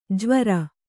♪ jvara